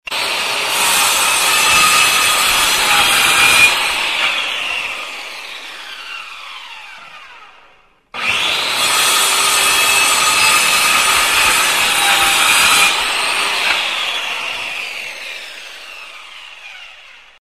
На этой странице собраны реалистичные звуки работающих станков: токарных, фрезерных, шлифовальных и других.
Звук болгарки режущей металл